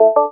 kling.wav